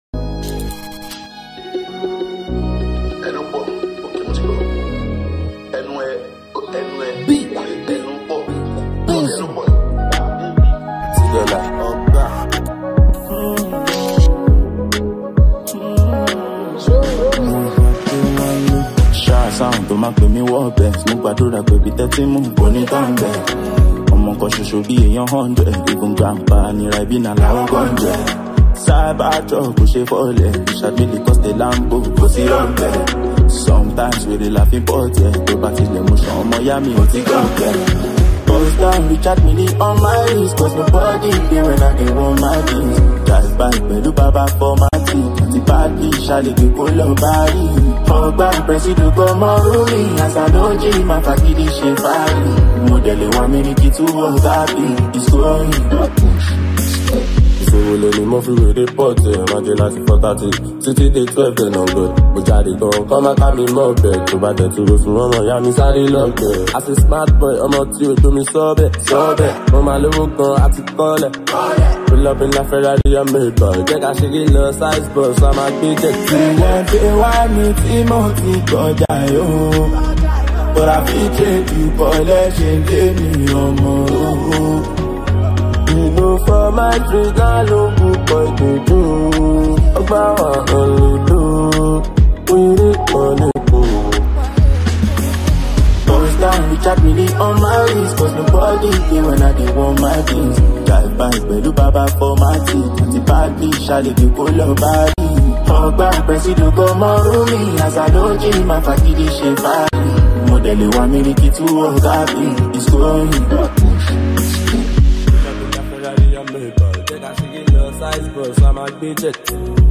contributed vocals to the record.